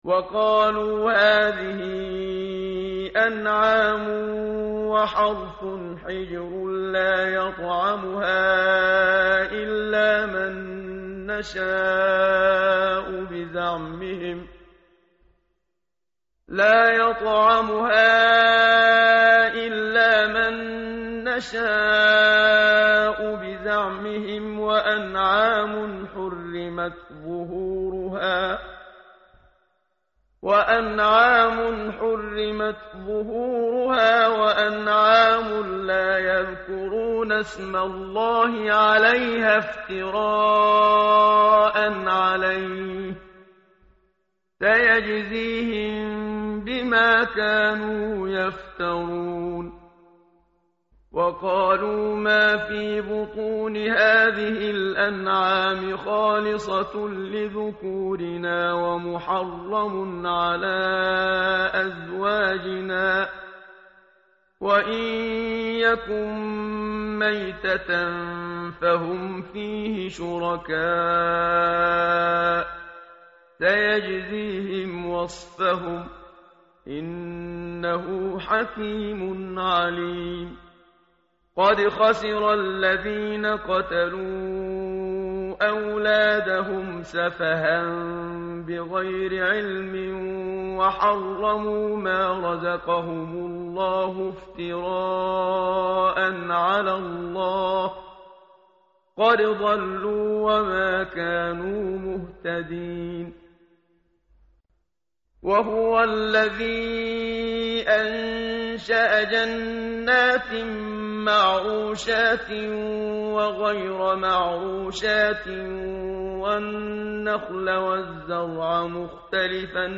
متن قرآن همراه باتلاوت قرآن و ترجمه
tartil_menshavi_page_146.mp3